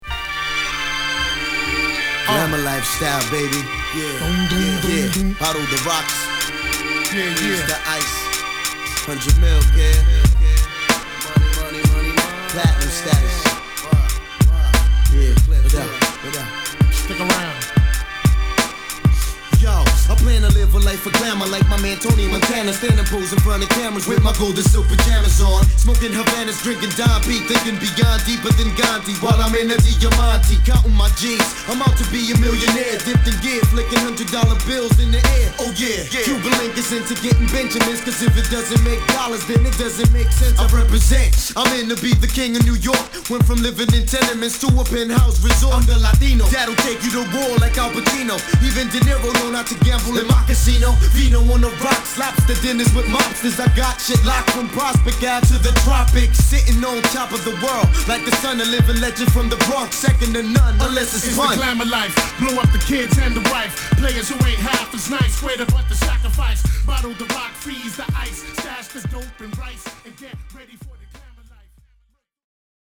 ・ HIP HOP 90's 12' & LP